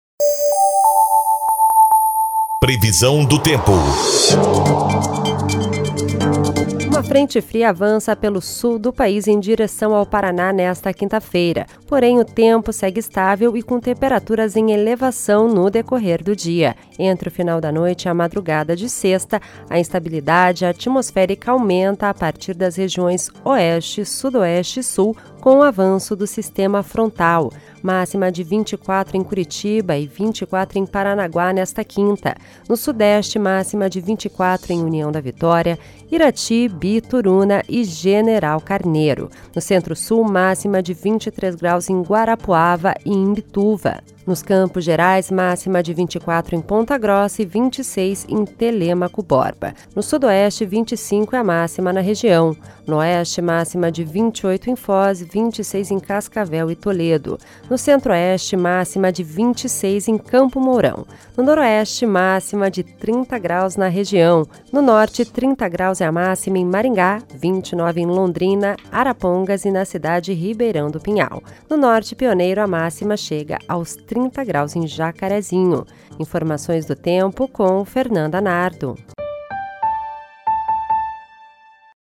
Previsão do Tempo (28/07)